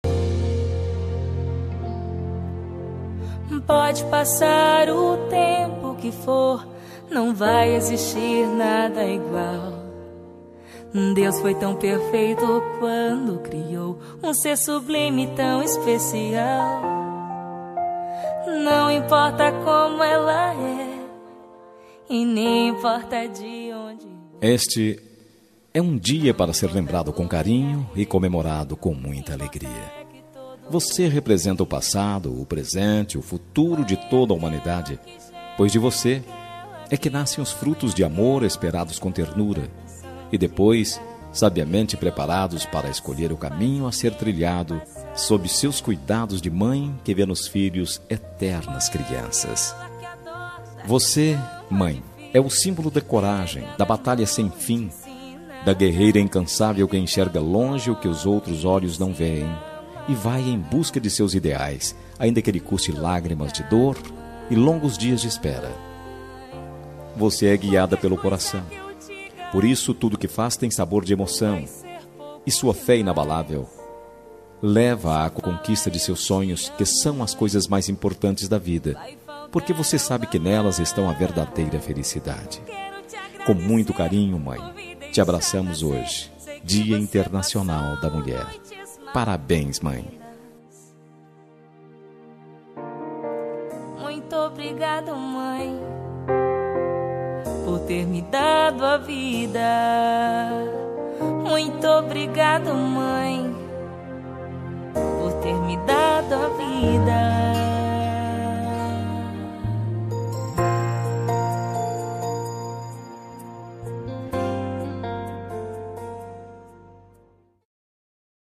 Dia das Mulheres Para Mãe – Voz Masculina – Cód: 5336 – Linda
5336-dm-mae-masc.m4a